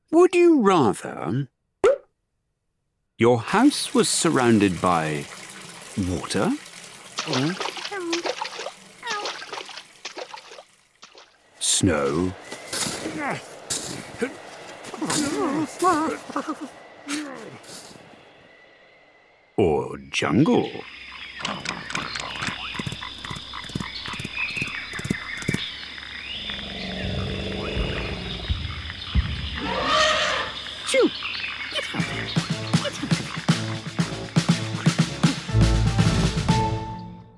Avocado Baby and Other Stories Audiobook
Read by Alexander Armstrong.